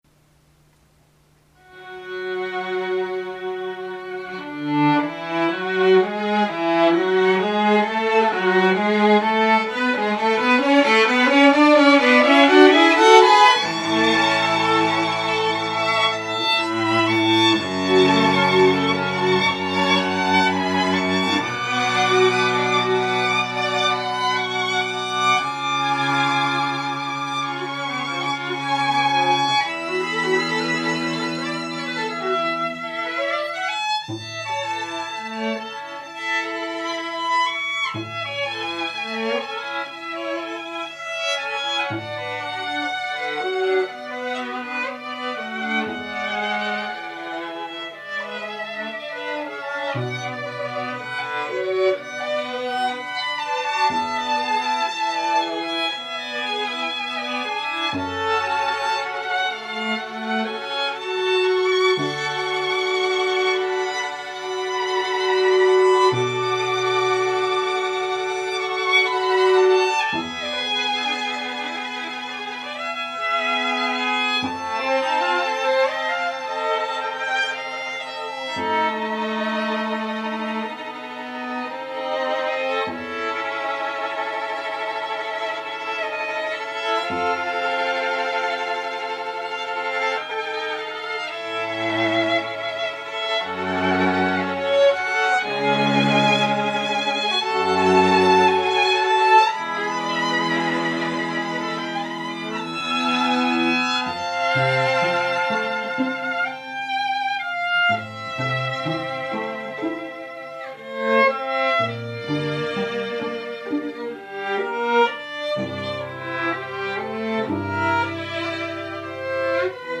演奏は、ノン・ジャンル弦楽四重奏団≪モーメント・ストリングカルテット≫です。